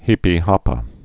(hēpē-häpə)